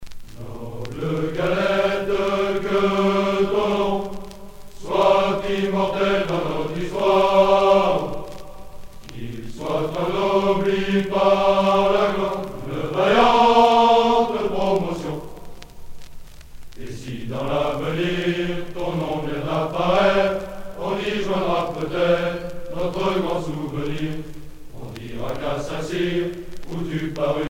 circonstance : militaire
Pièce musicale éditée